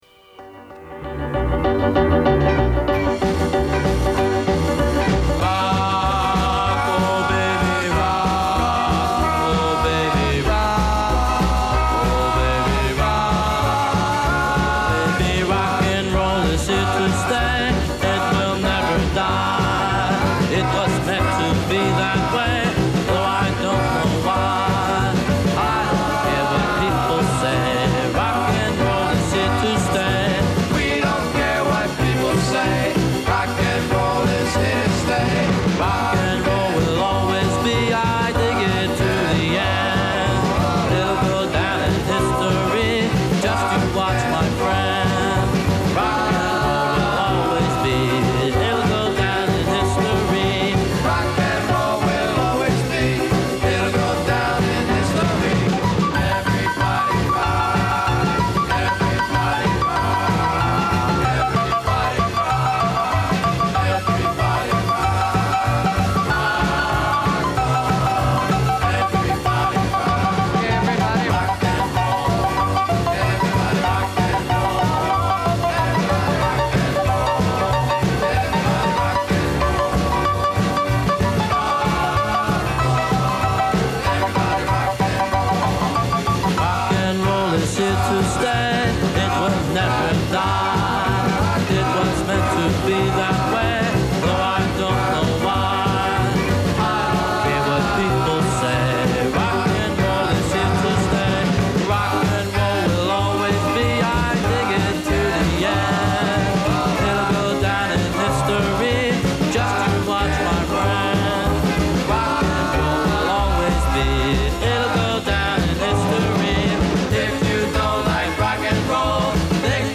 Radio Greats Weekend: Alan Freed Tribute [scoped-stereo]
There are excerpts from his WINS and WABC-AM shows as well as from a few recordings that he made, including a novelty record that anticipates what Dickie Goodman would do just a few years later.
While Freed and rock 'n roll music in general was highly controversial in the 1950s, Freed himself was very low-key, spoke in conversational tones on the air (unlike the screamers and speed-talkers of the time) and dressed in conservative suits.